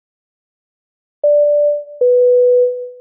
На этой странице собраны реалистичные звуки двигателя самолета: от плавного гула турбин до рева при взлете.
Звук вызова стюардессы кнопкой